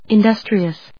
音節in・dus・tri・ous 発音記号・読み方
/ɪndˈʌstriəs(米国英語), ˌɪˈndʌstri:ʌs(英国英語)/